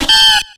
Cri de Macronium dans Pokémon X et Y.